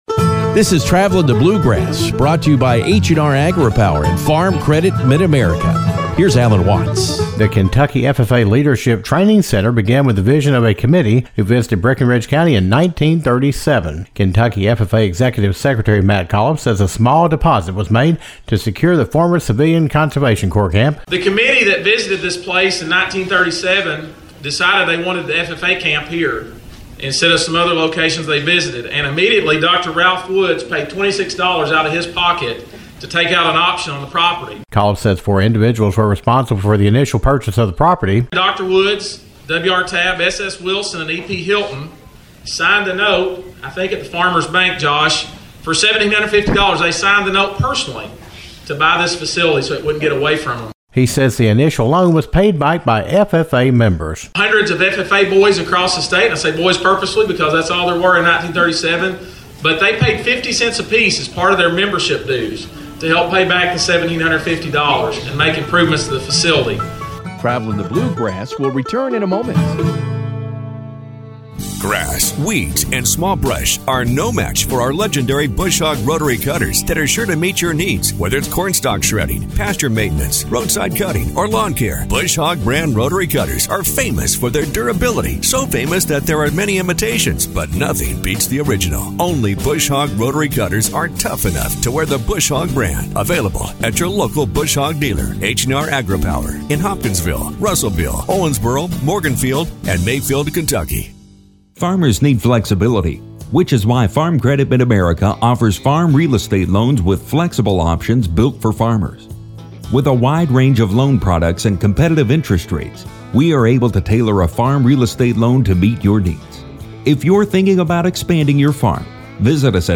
took time during the recent groundbreaking ceremony to talk about how the center began and how FFA members and leaders have developed the facilities since the beginning.